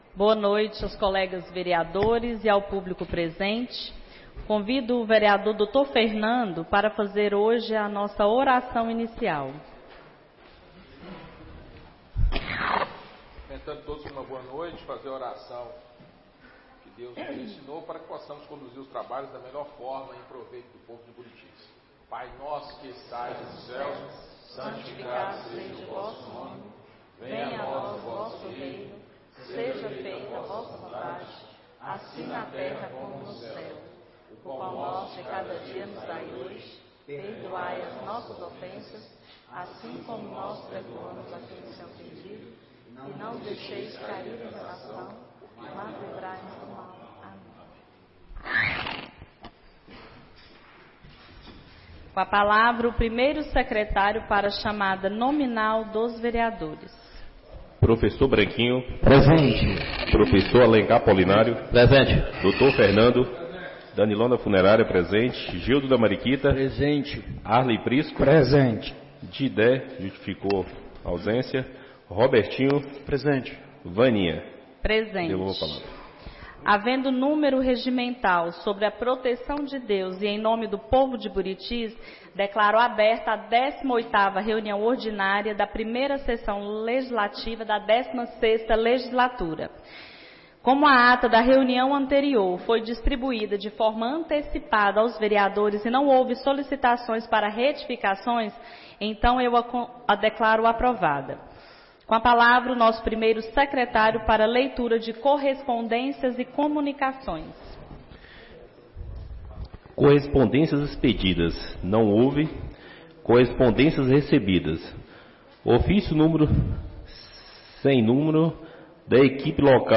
18ª Reunião Ordinária da 1ª Sessão Legislativa da 16ª Legislatura - 19-05-25